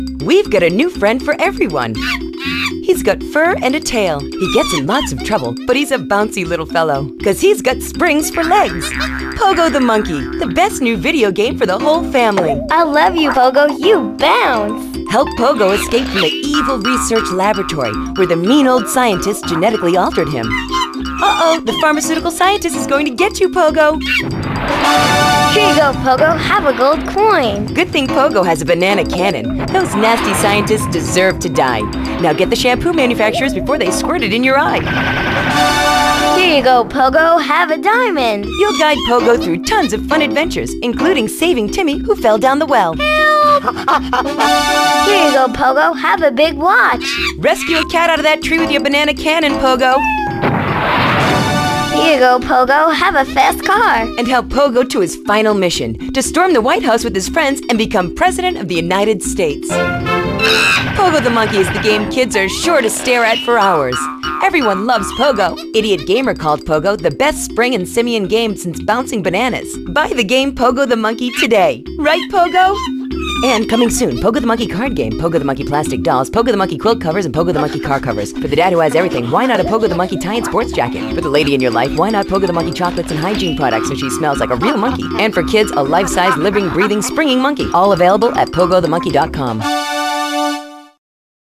[[Category:Audio ads]]